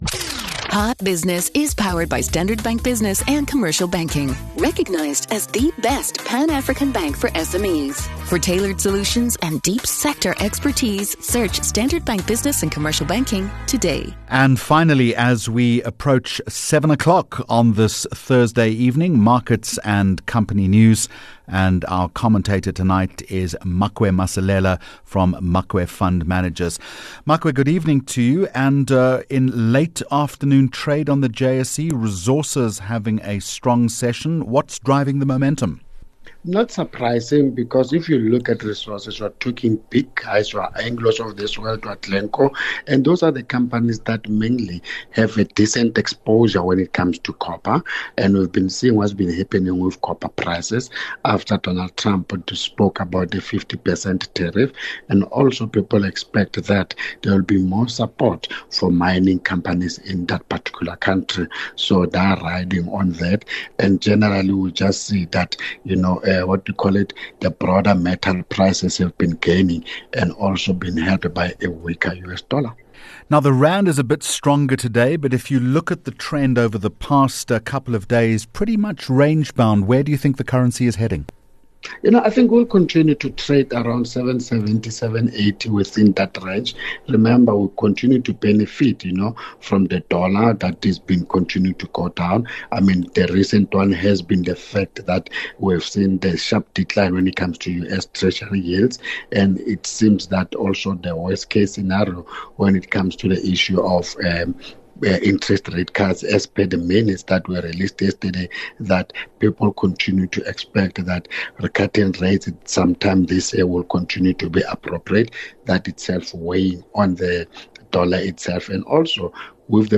10 Jul Hot Business Interview